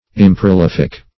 Search Result for " improlific" : The Collaborative International Dictionary of English v.0.48: Improlific \Im`pro*lif"ic\, a. [Pref. im- not + prolific: cf. F. improlifique.]